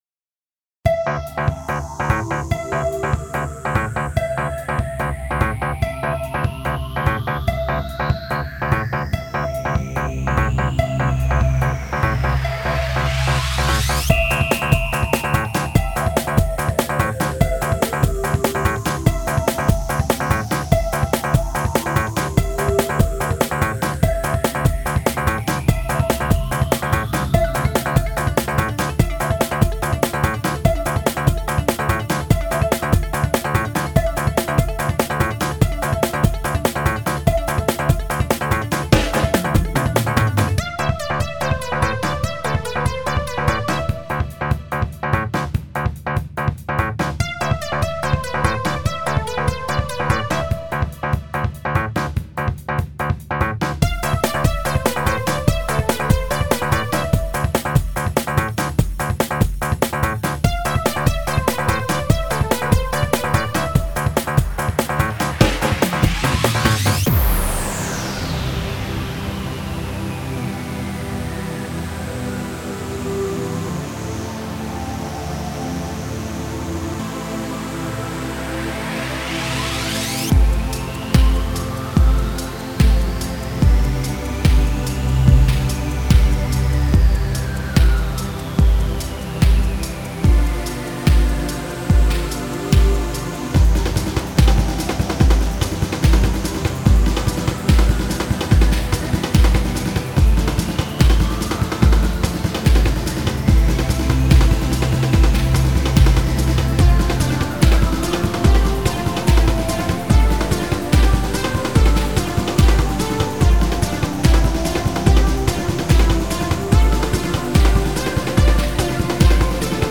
EDMロング激しい